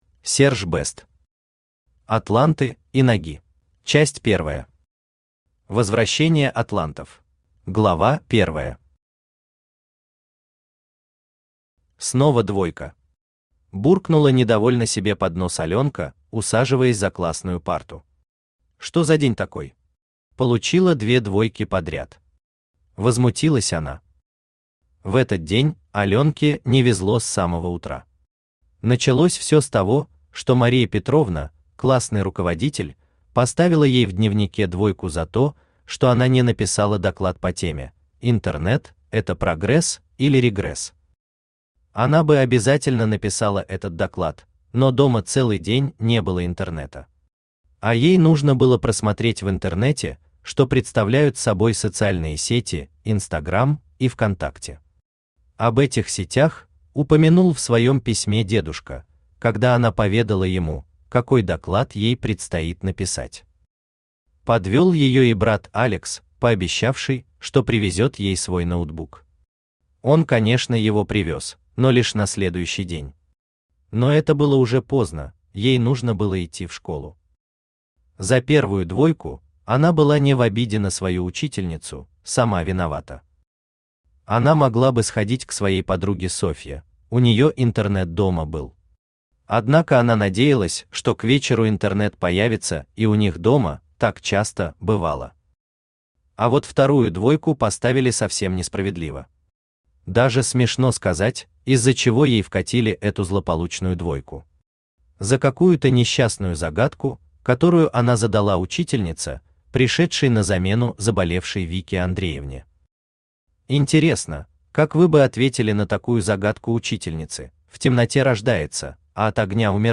Aудиокнига Атланты и Наги Автор Серж Бэст Читает аудиокнигу Авточтец ЛитРес.